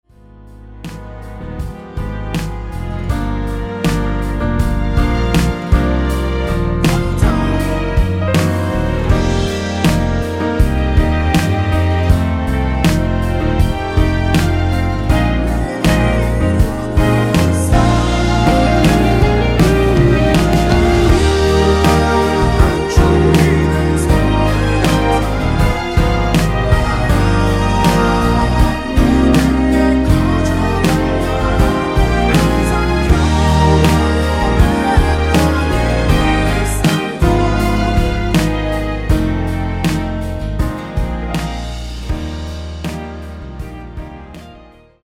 원키에서(-1)내린 코러스 포함된 MR 입니다.(미리듣기 참조)
Eb
앞부분30초, 뒷부분30초씩 편집해서 올려 드리고 있습니다.
중간에 음이 끈어지고 다시 나오는 이유는